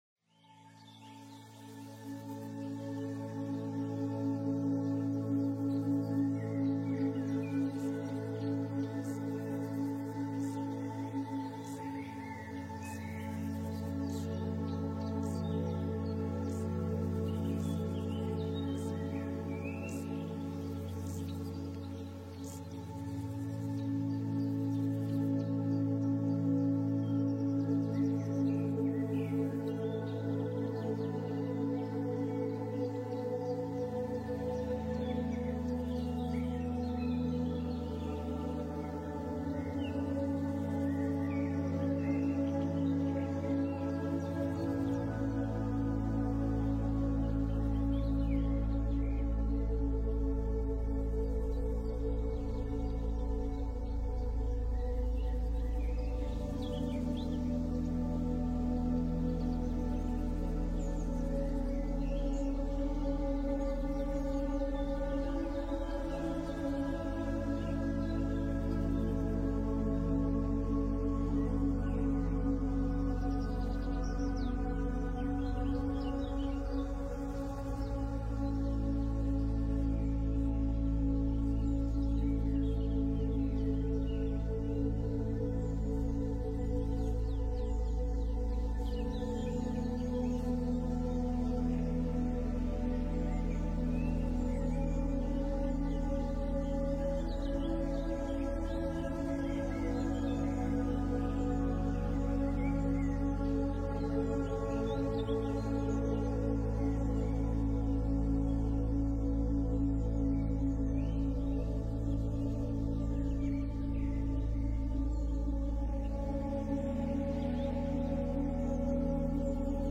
Soundscape Final DTS Version.mp3